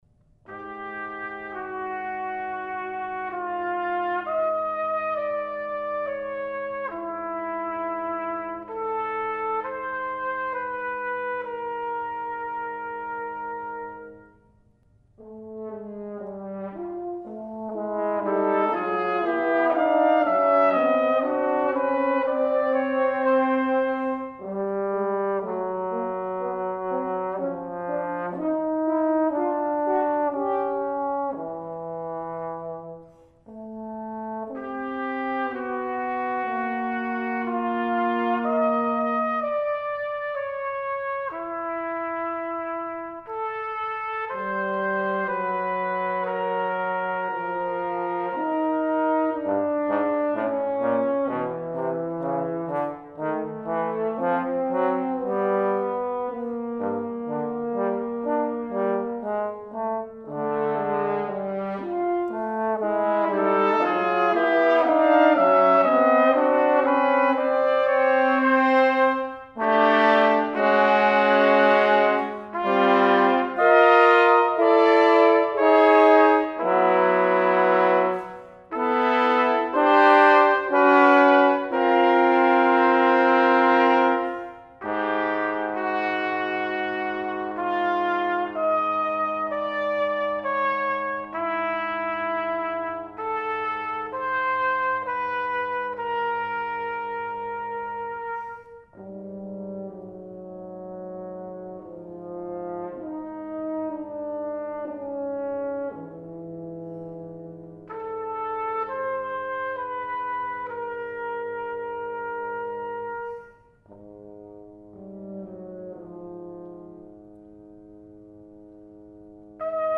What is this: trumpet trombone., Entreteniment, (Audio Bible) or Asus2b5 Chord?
trumpet trombone.